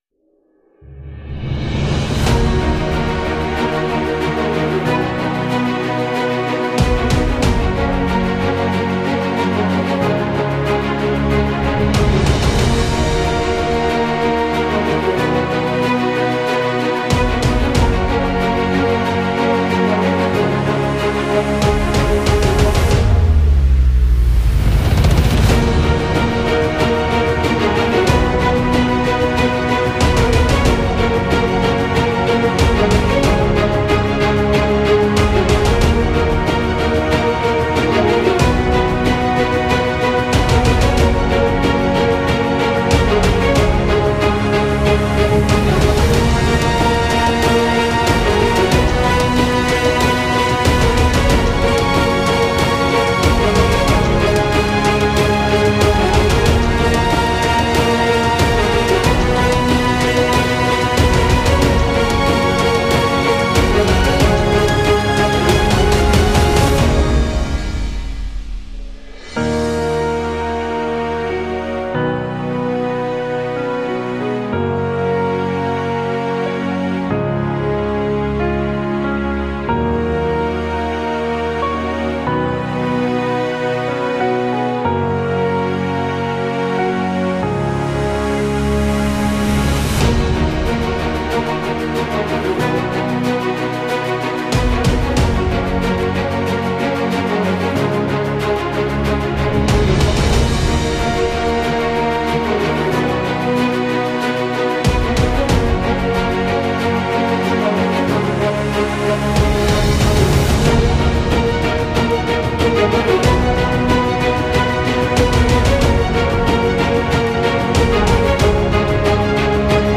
宣传片配音勇往直前震撼大气史诗